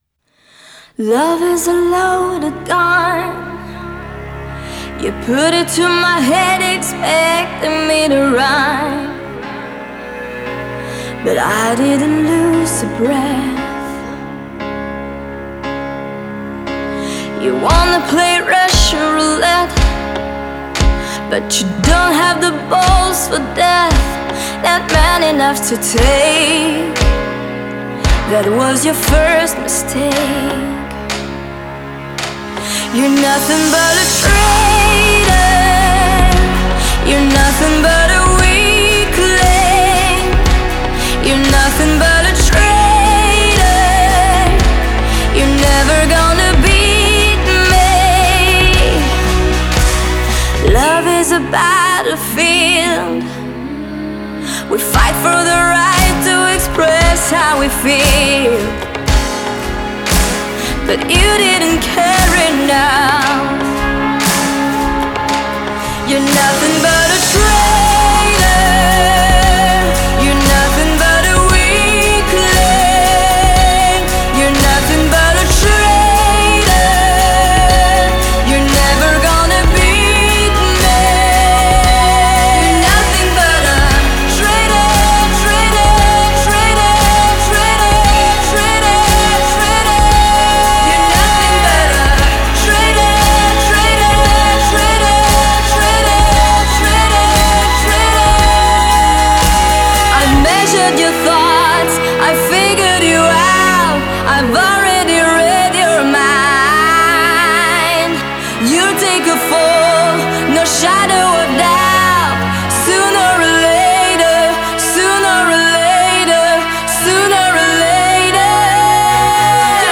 Genre: Pop, Female vocalists